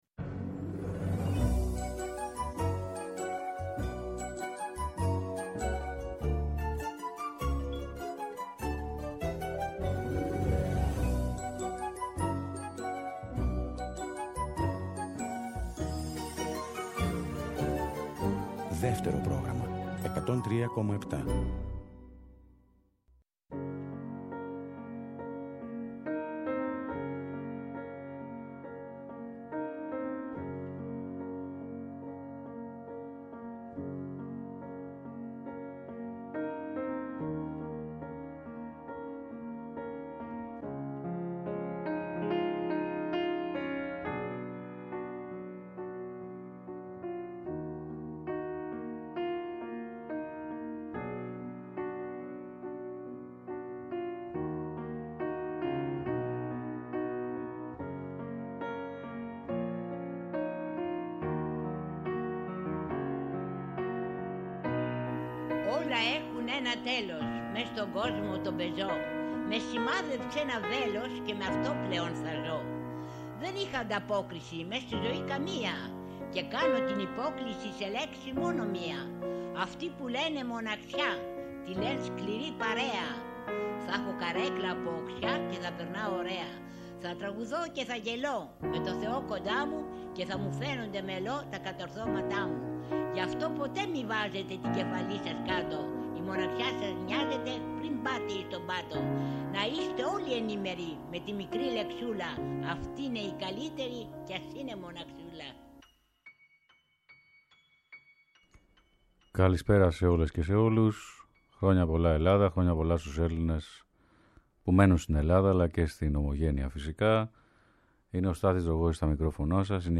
Τραγούδια ελληνικά περήφανα και σκωπτικά αυτό το Σάββατο